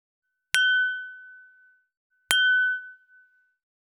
338ガラスのグラス,ウイスキー,コップ,食器,テーブル,チーン,カラン,キン,コーン,チリリン,カチン,チャリーン,クラン,カチャン,クリン,シャリン,チキン,コチン,カチコチ,チリチリ,シャキン,
コップ